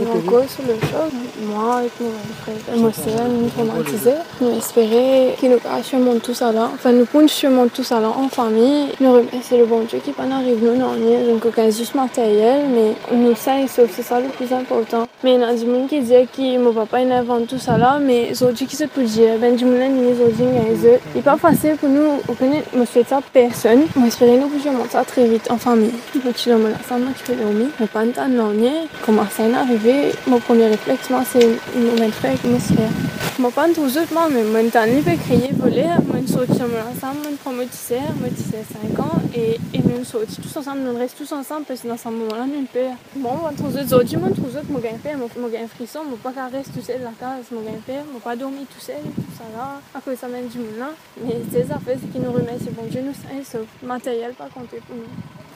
Elle s’est confiée à l’express lors d’une reconstitution des faits chez elle, lundi 19 septembre.